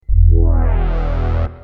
Free MP3 vintage Roland JX8P loops & sound effects 3
Synthetisers Soundbank